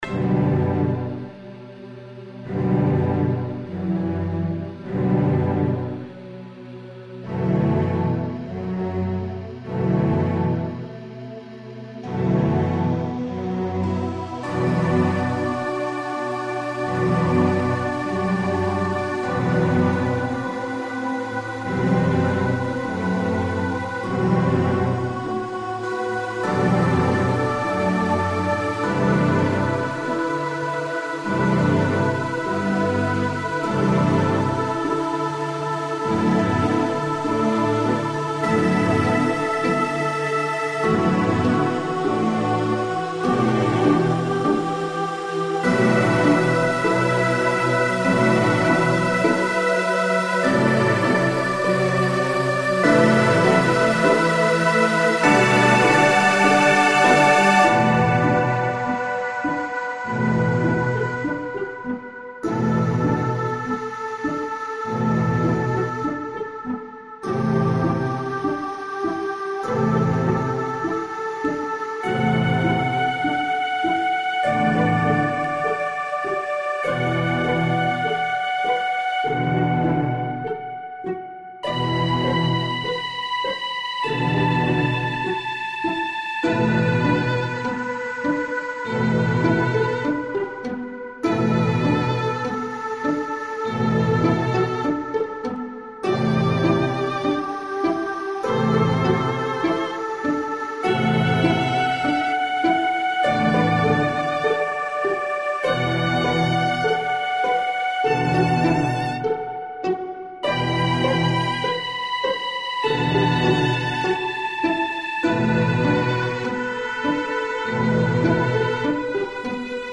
Crawling - Orchestral/Electronic